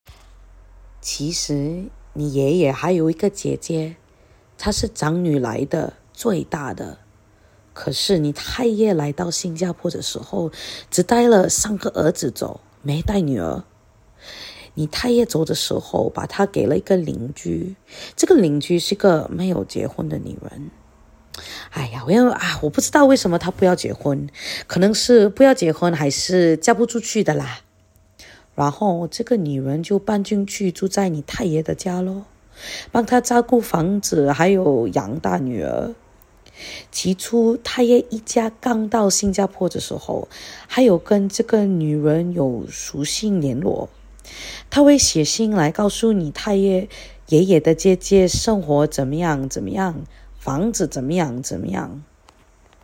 husky